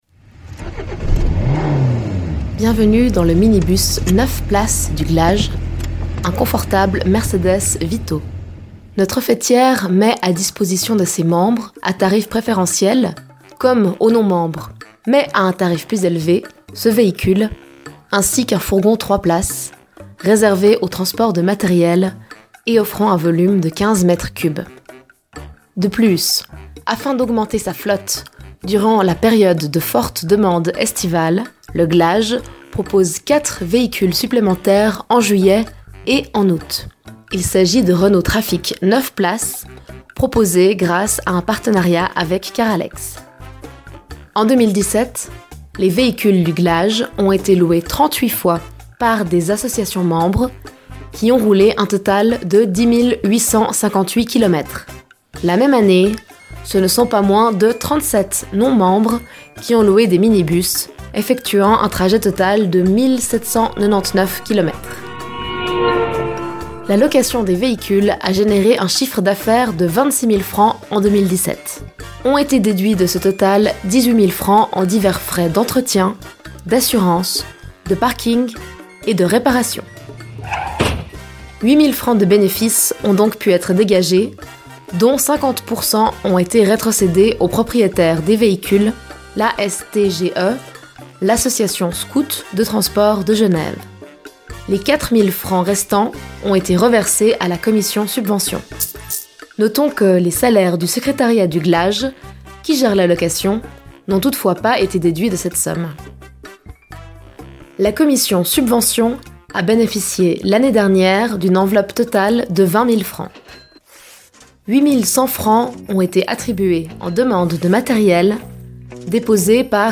visite guidée des services aux membres: minibus, mais également subventions, impressions, boîte aux lettres, salle de conférence, beamer…